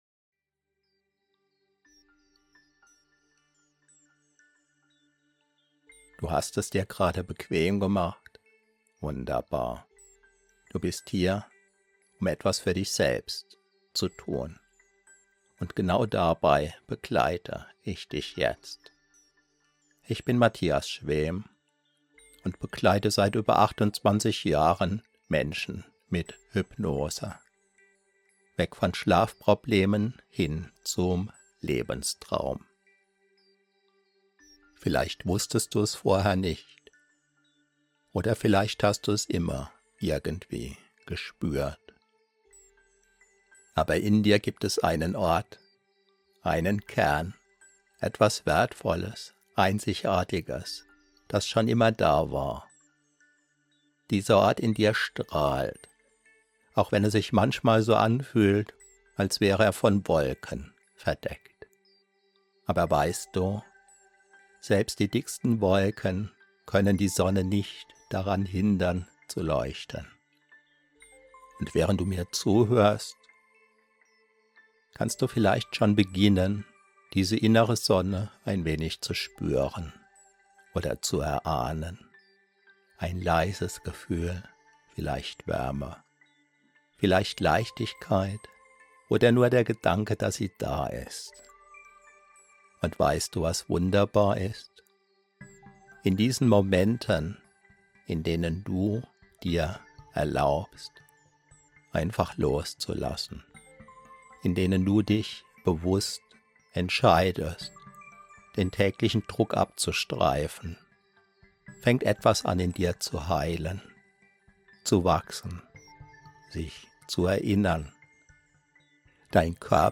1 ✨ Schlafhypnose zur Stärkung deines Selbstwerts aus 28 Jahren Hypnose-Praxis - ULTRA STARK! 51:43